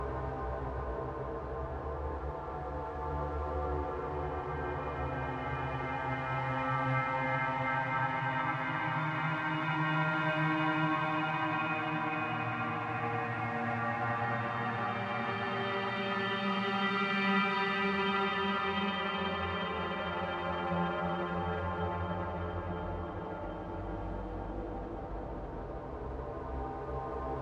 环境现实Pad 4
Tag: 140 bpm Ambient Loops Pad Loops 4.61 MB wav Key : D